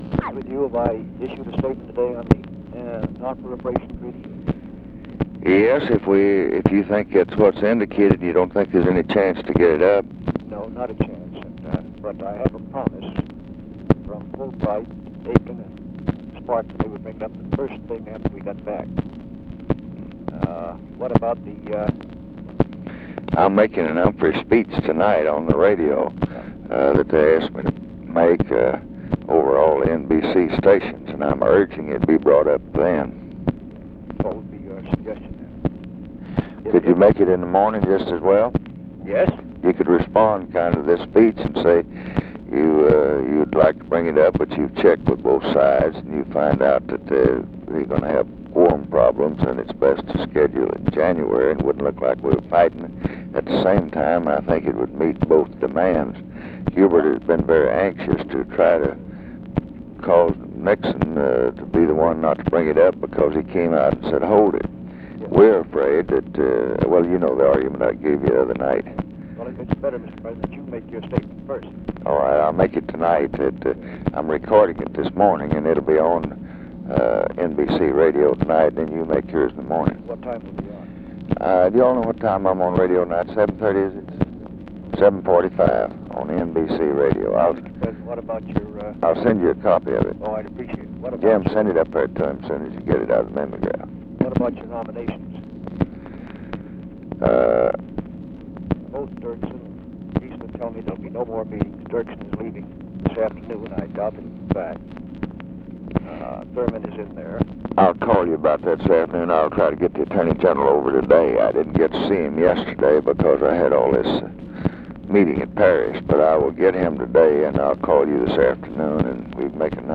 Conversation with MIKE MANSFIELD, October 10, 1968
Secret White House Tapes